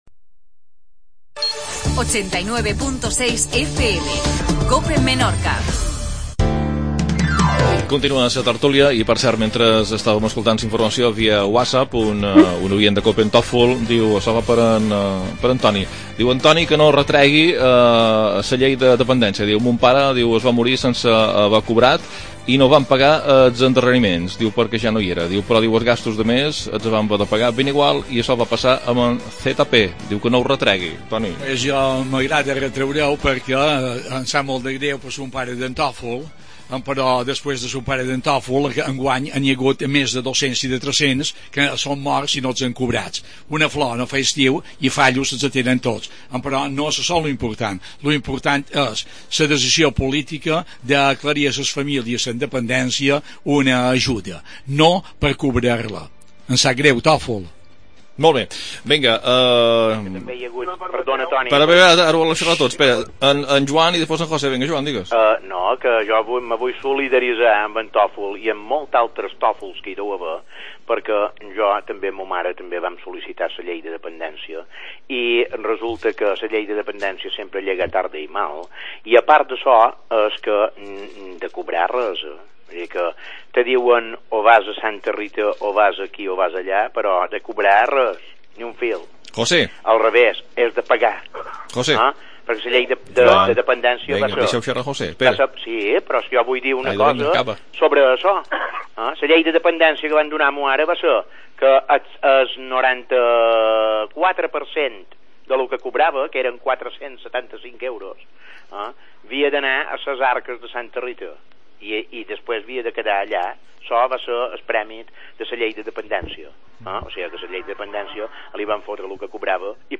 Tertulia, segona part.